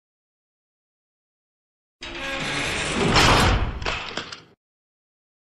دانلود صدای باز و بسته شدن در زندان 2 از ساعد نیوز با لینک مستقیم و کیفیت بالا
جلوه های صوتی